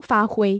发挥 fāhuī 発揮する